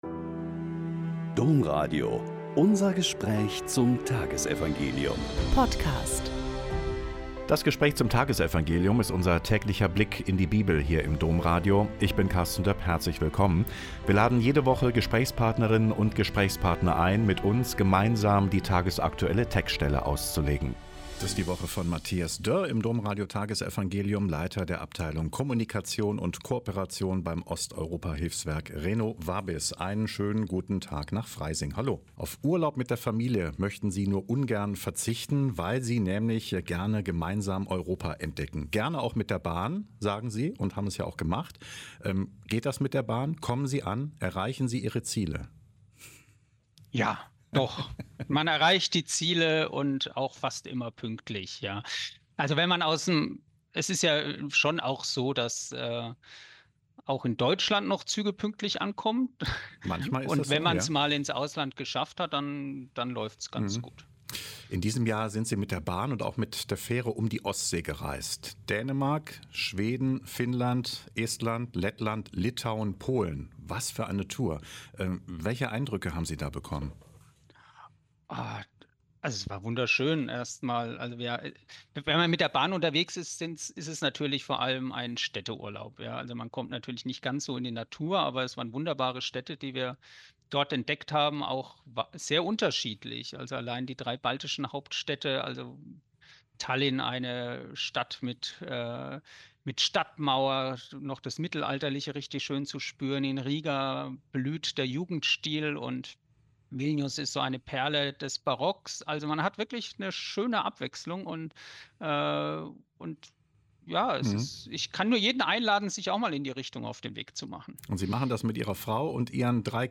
Lk 13,22-30 - Gespräch